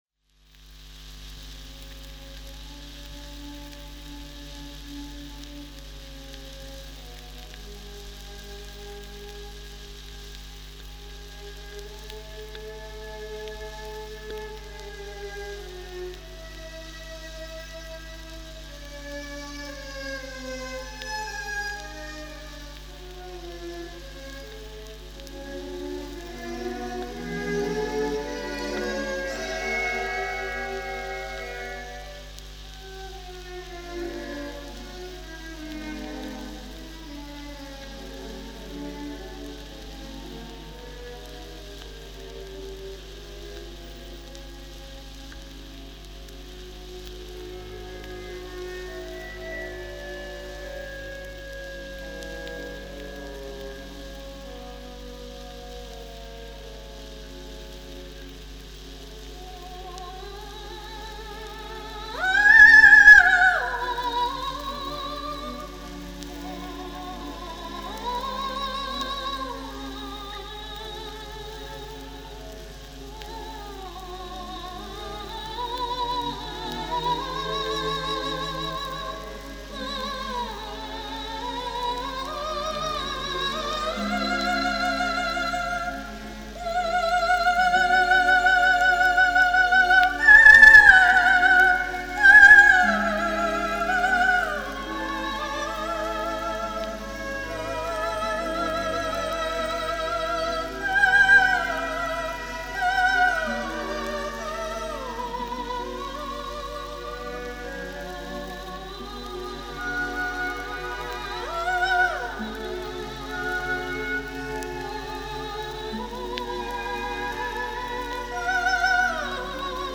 ВОКАЛИЗ – это произведение, написанное для  голоса без слов.
* 04 Gohar Gasparyan - Концерт для голоса и оркестра part 1 (R.Gliere)